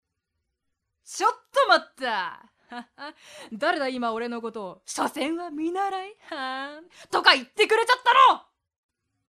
【サンプルセリフ】
（ツッコミ！）